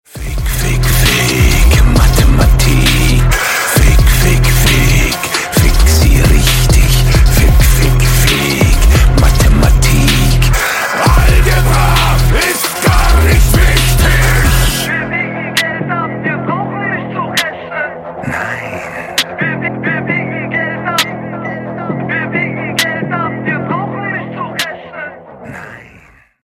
Рок Металл Рингтоны